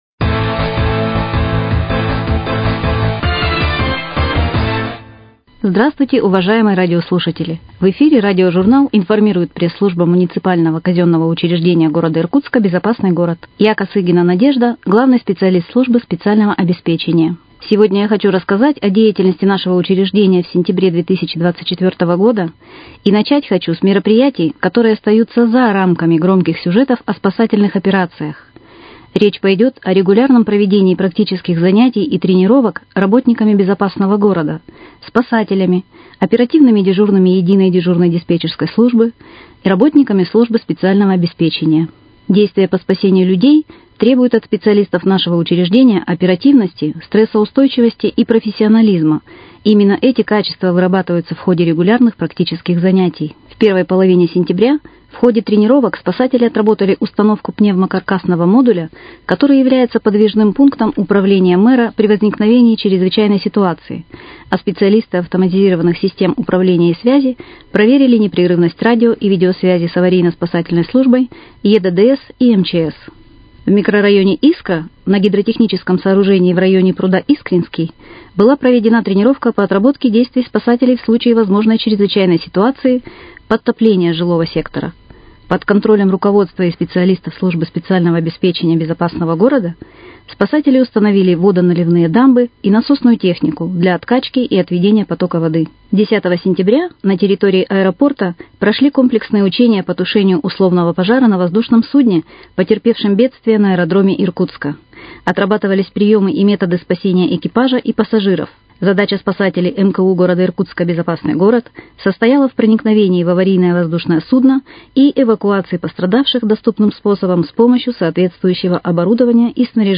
Радиожурнал «Информирует МКУ «Безопасный город»: О работе ЕДДС